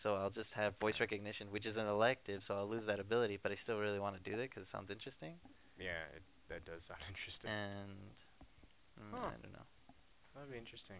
Supporting Information for Analyses of L2 English
2. "interesting" (agreement): Dimension 10 low